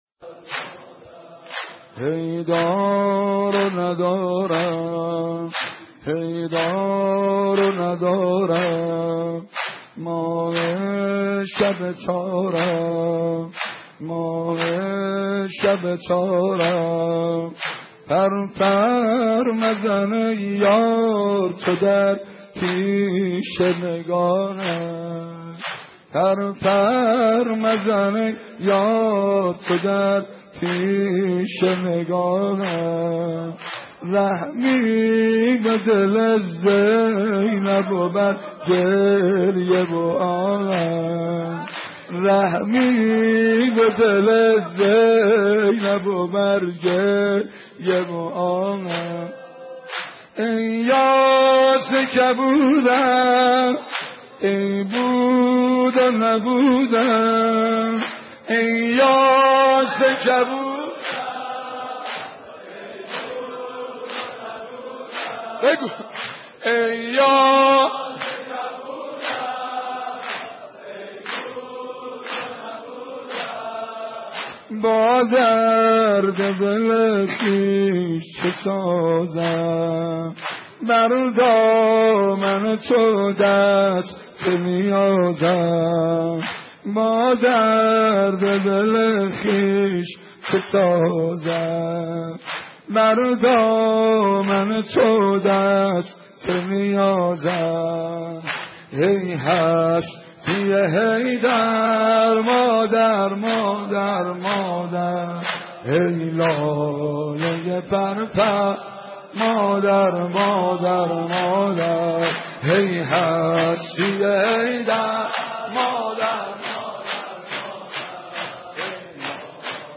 دانلود مداحی ای دار ندارم - دانلود ریمیکس و آهنگ جدید